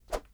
sfx_action_throw_04.wav